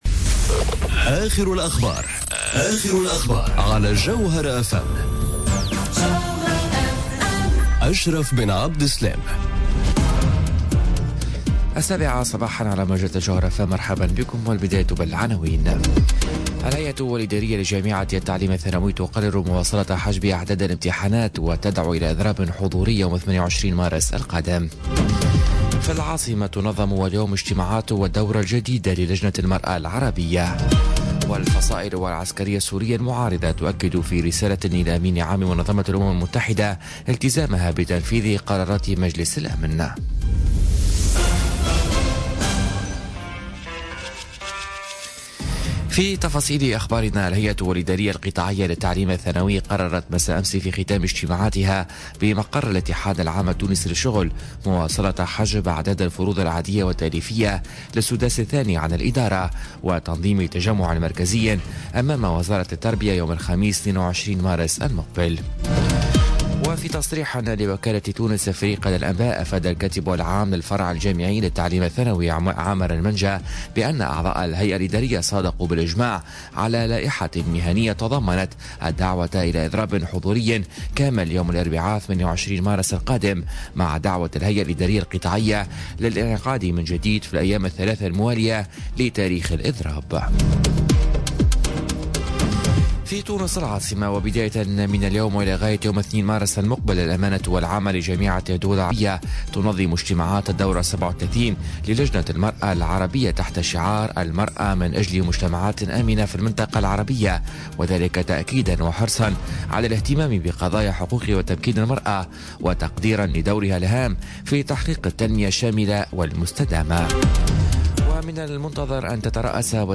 نشرة أخبار السابعة صباحا ليوم الأربعاء 28 فيفري 2018